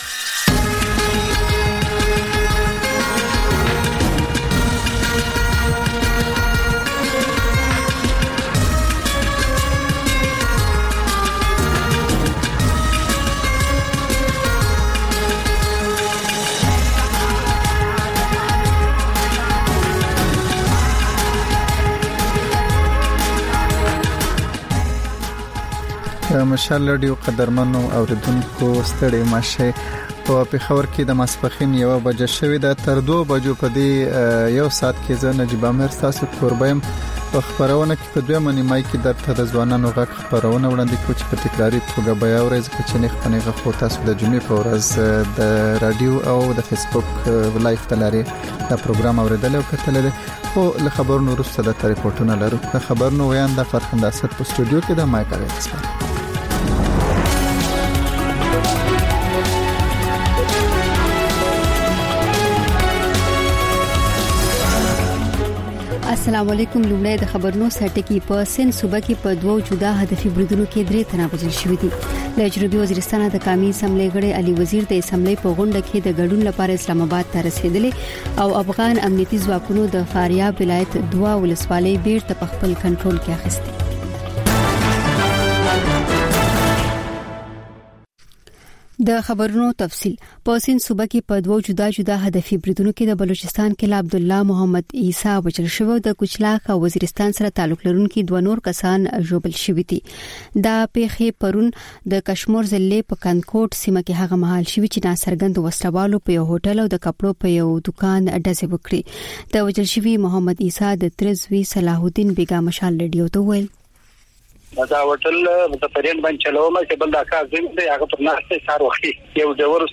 د مشال راډیو لومړۍ ماسپښينۍ خپرونه. په دې خپرونه کې تر خبرونو وروسته بېلا بېل رپورټونه، شننې، مرکې خپرېږي. ورسره یوه اوونیزه خپرونه هم خپرېږي.